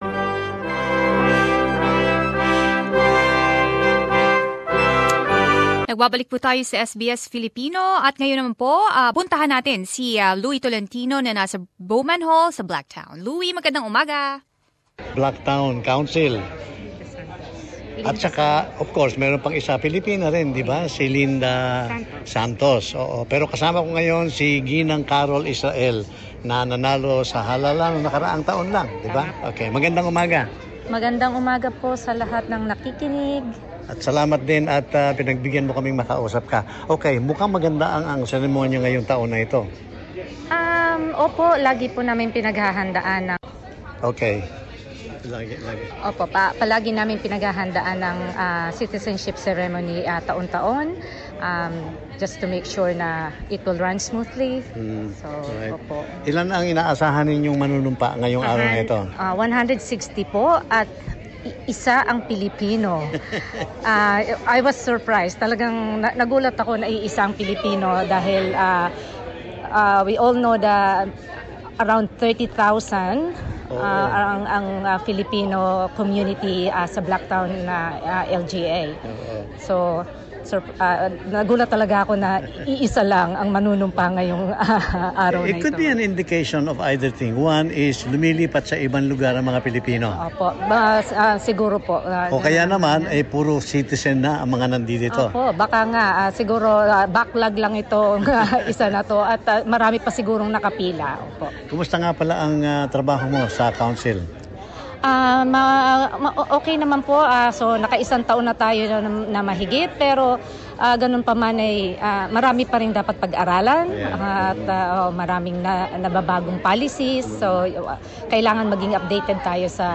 talks to 2 Filipino Councilors , Carol Israel and Linda Santos about this and the future plans of the council for the years to come.